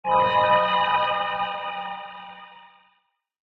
cave1.ogg